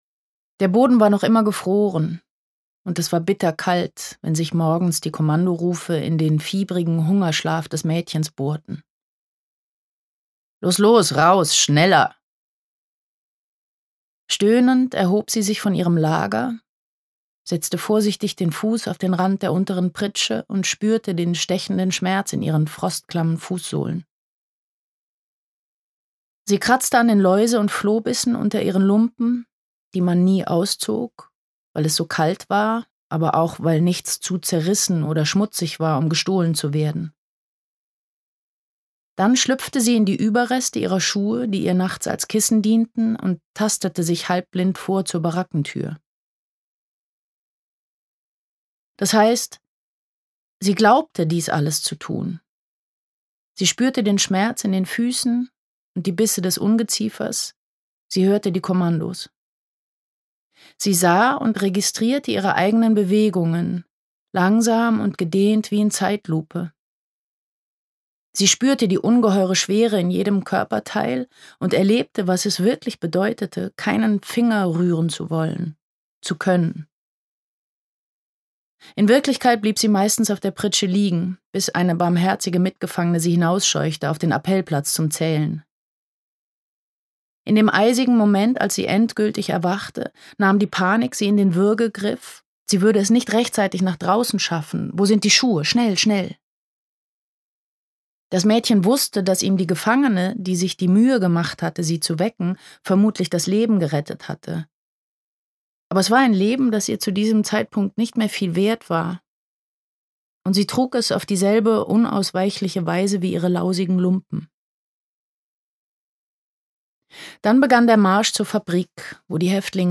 Gebranntes Kind sucht das Feuer Roman Cordelia Edvardson (Autor) Nina Kunzendorf , Ulrich Noethen (Sprecher) Audio-CD 2023 | 1.